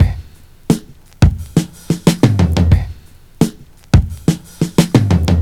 Tuned drums (F key) Free sound effects and audio clips
• 118 Bpm Breakbeat Sample F Key.wav
Free drum groove - kick tuned to the F note. Loudest frequency: 639Hz
118-bpm-breakbeat-sample-f-key-K6L.wav